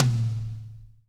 -DRY TOM 4-R.wav